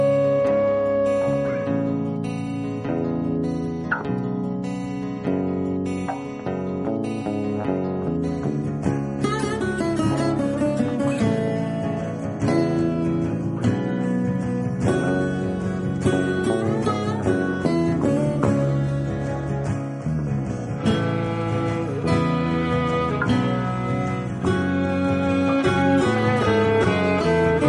Devotional Ringtones
Instrumental Ringtones